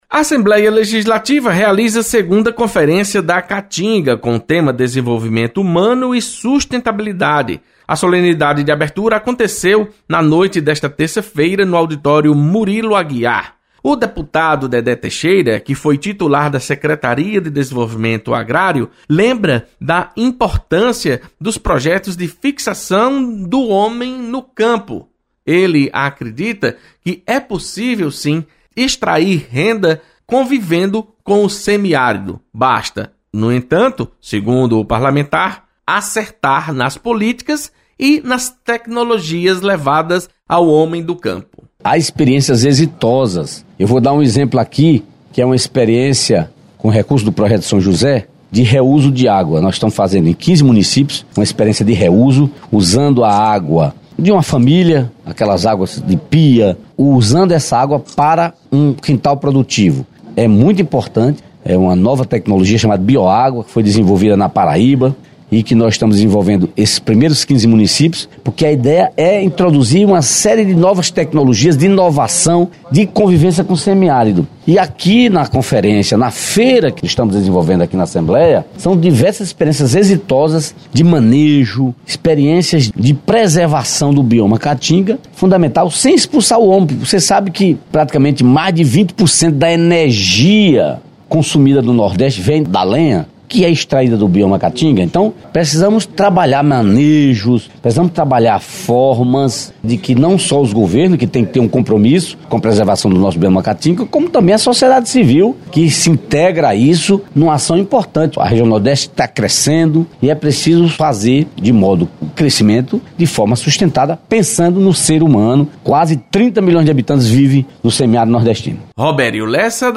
Deputado Dedé Teixeira destaca discussão sobre tecnologia para convívio com o Bioma Caatinga.